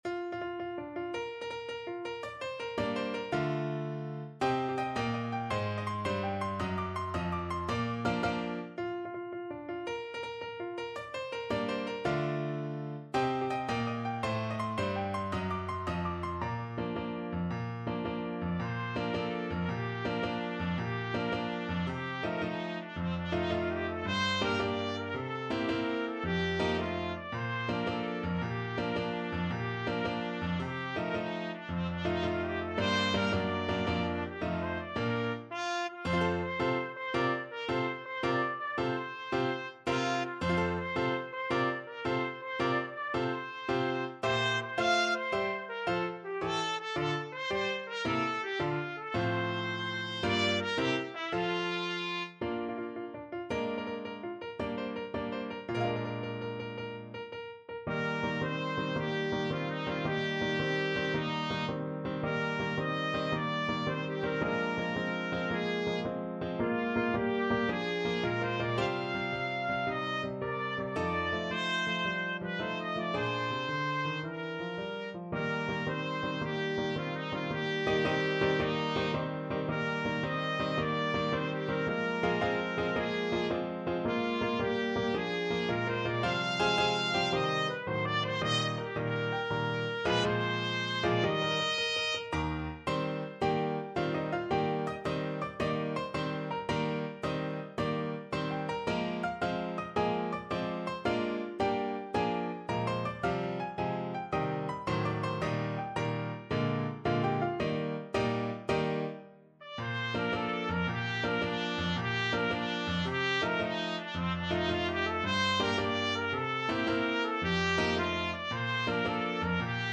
6/8 (View more 6/8 Music)
March .=c.110
Classical (View more Classical Trumpet Music)